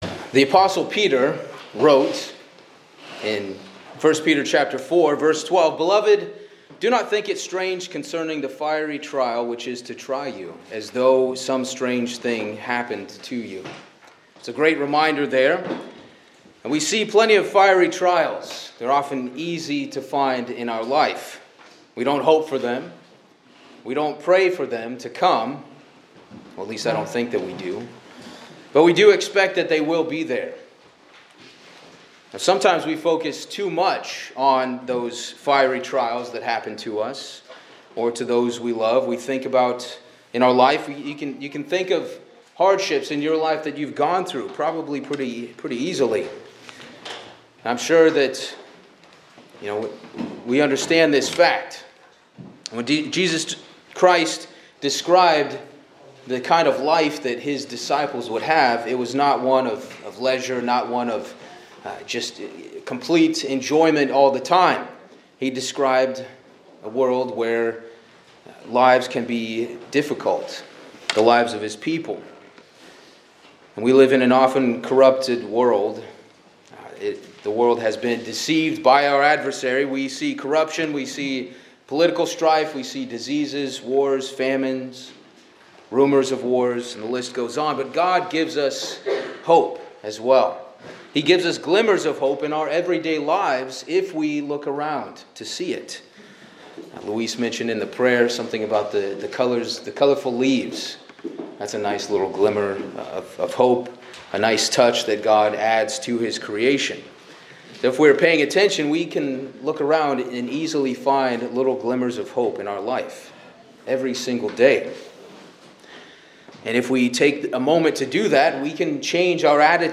This sermon focuses on the theme of blessings, particularly as described in the Bible.